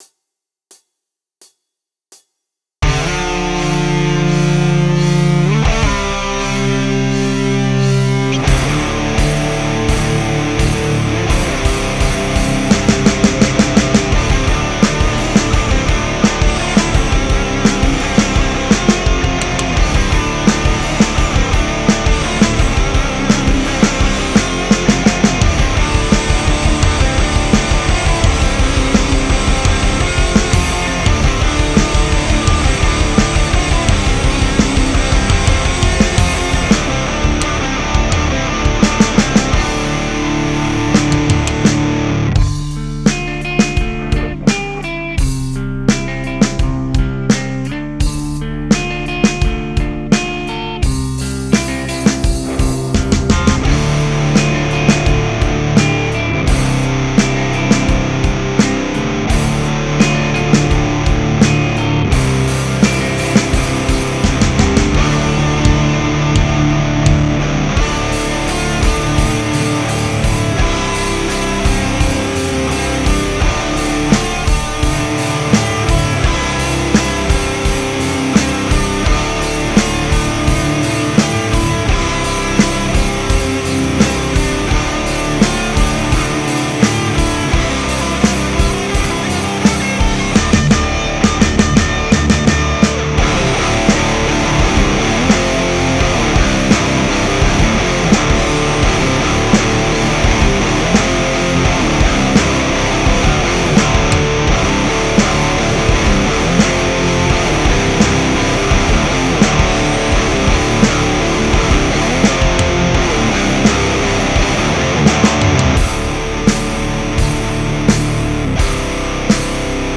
Il n'y a pas la basse.
Guitare > Audiobox VSL 22 > Revalver MkII + Cubase
Piste midi GP6 > AddictiveDrum + Cubase
Vous remarquerez que le mix n'est pas au point (sauf chez moi...) et je sais qu'il me faut des enceintes de monitoring pour avoir un meilleur effet.
1) Comment rendre la distorsions moins étouffé" plus vivante ?